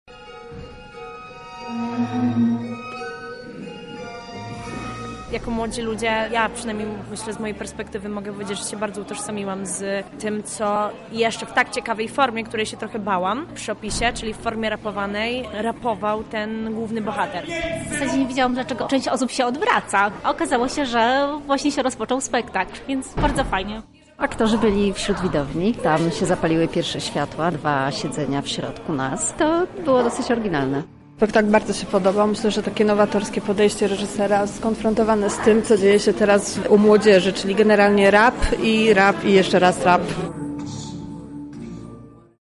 Na temat weekendowych występów wypowiedzieli się uczestnicy: